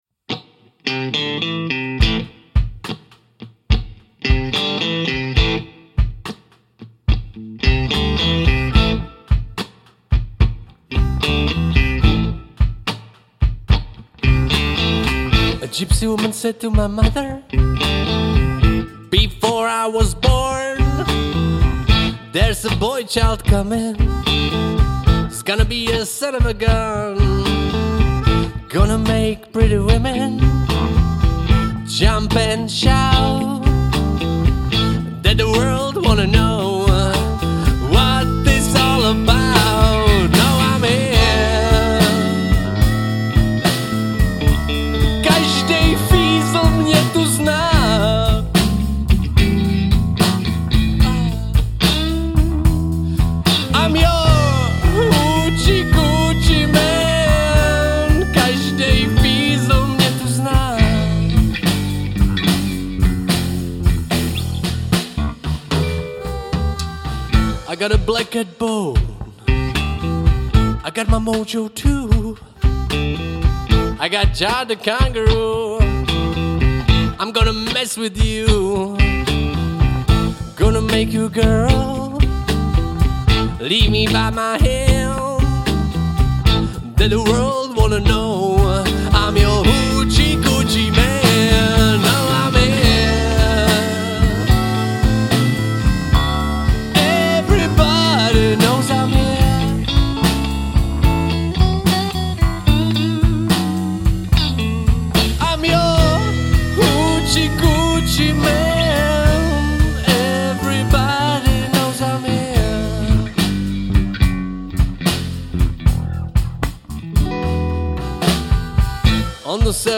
Žánr: Rock
Záznam z živé - studiové - videosession.